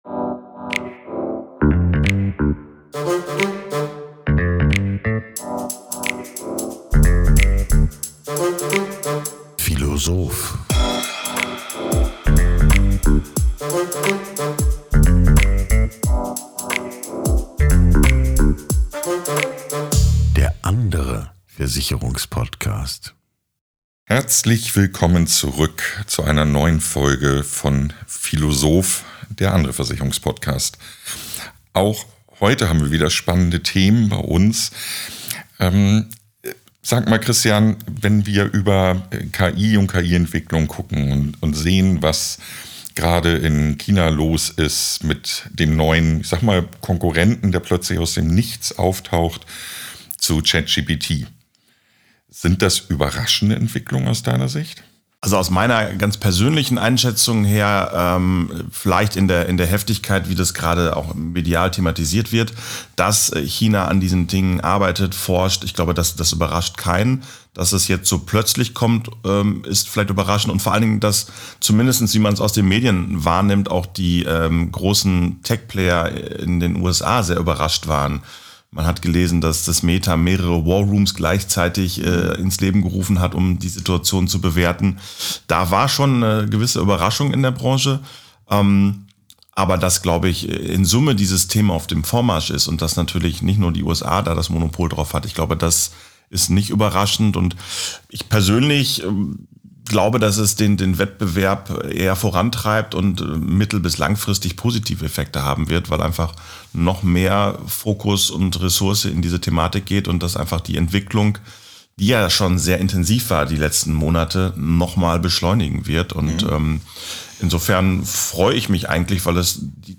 Ein Gespräch über smarte Lösungen, verändertes Kundenverhalten und die Frage, ob Rechtsschutz künftig mehr sein kann als eine Police in der Schublade.